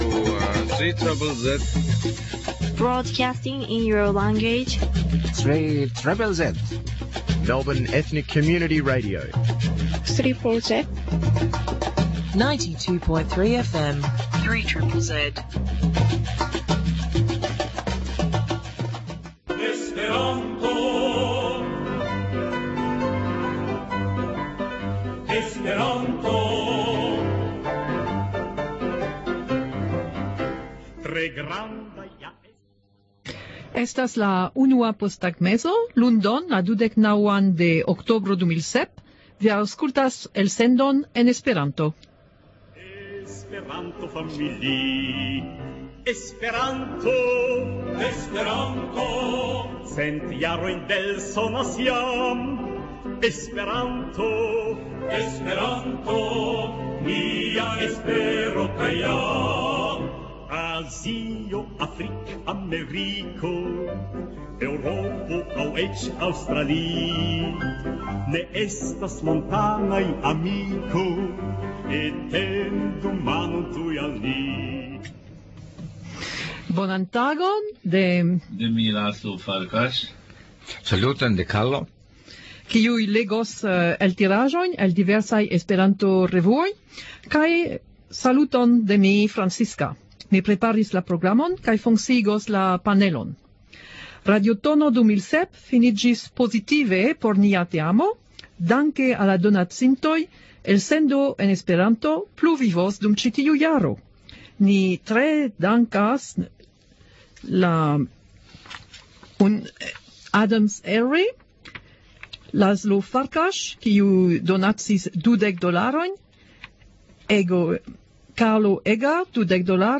Legaĵo